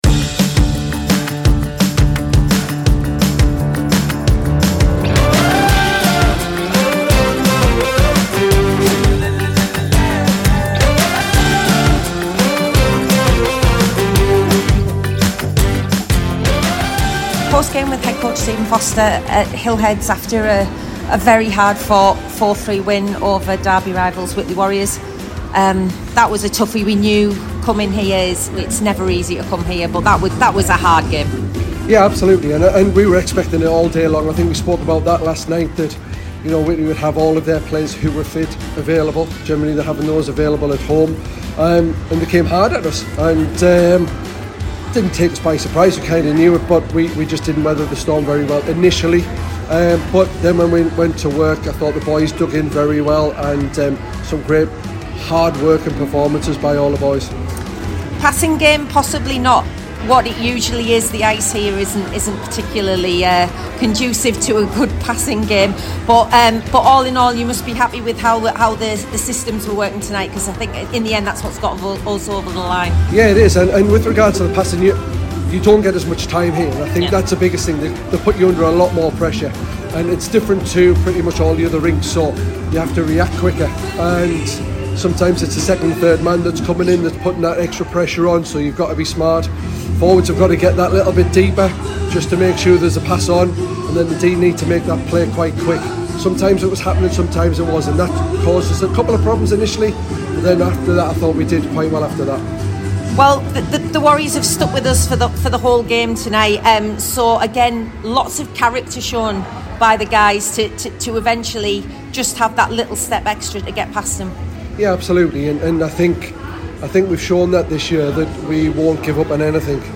Post-Game